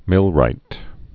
(mĭlrīt)